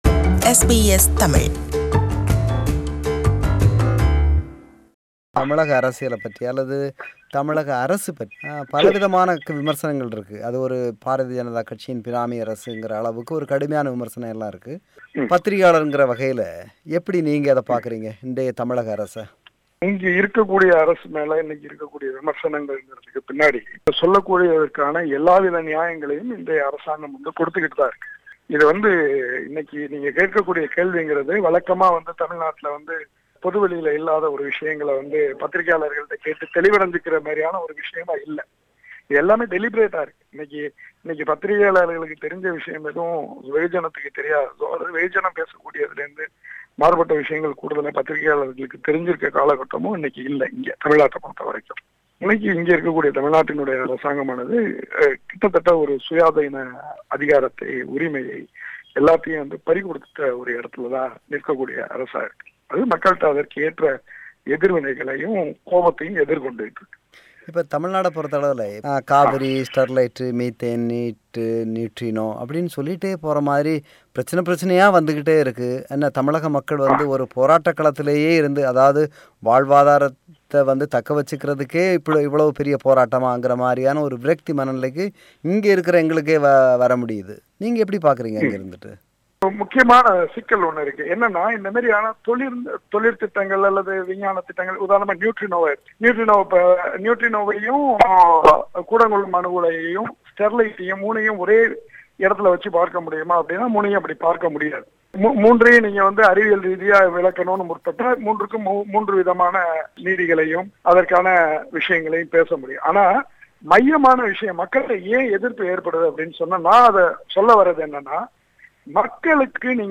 Interview – Part 3.